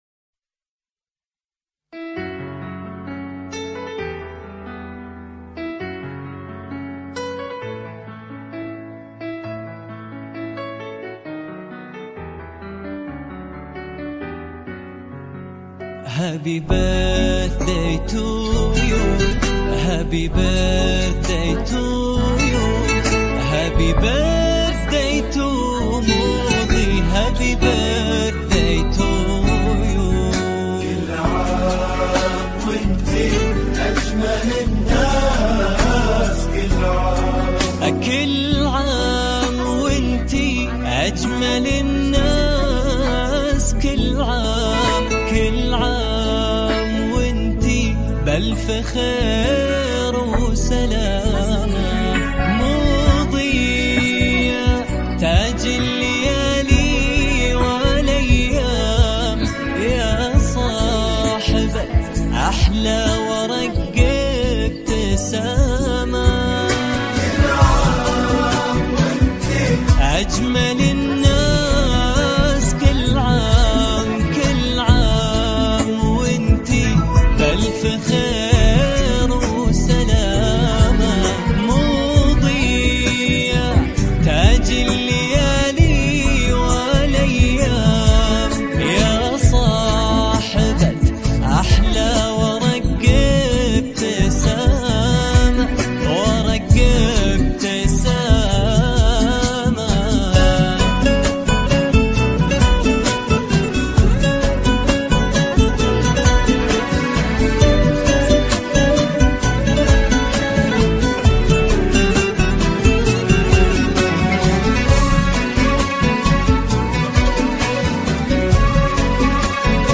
زفات موسيقى – زفات عيد ميلاد